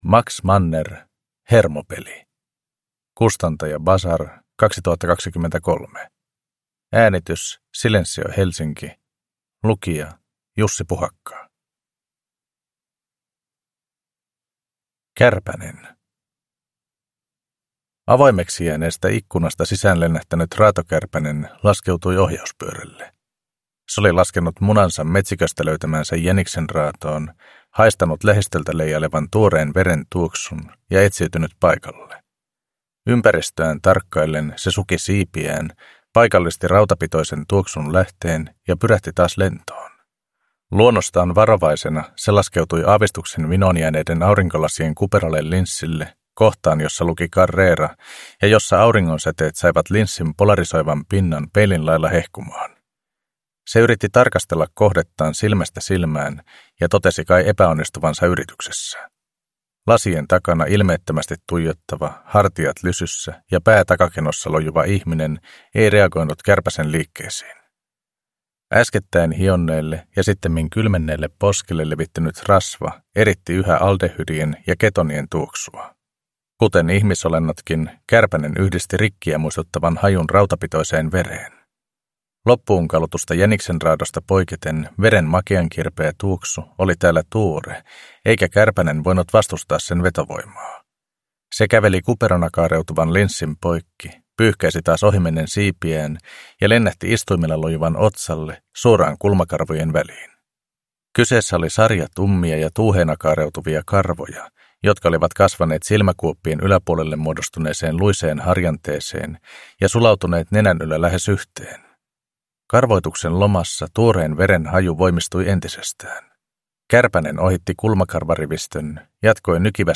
Hermopeli – Ljudbok – Laddas ner